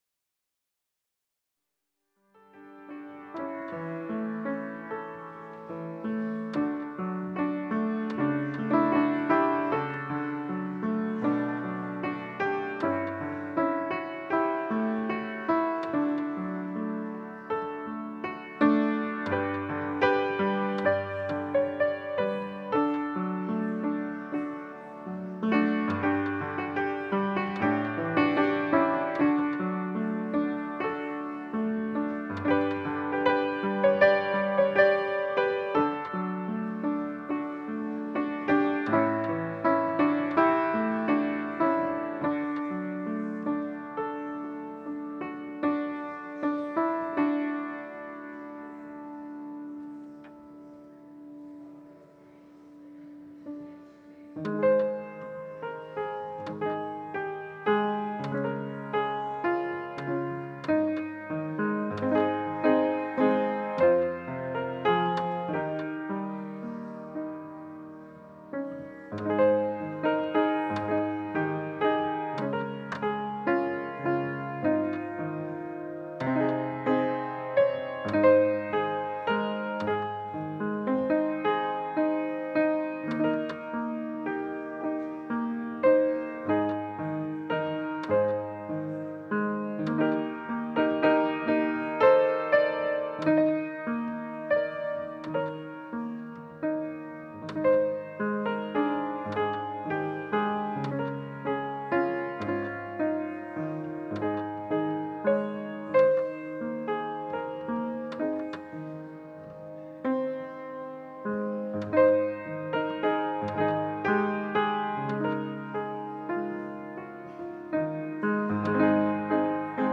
Passage: Leviticus 25: 8-17 Service Type: Holy Day Service Scriptures and sermon from St. John’s Presbyterian Church on Sunday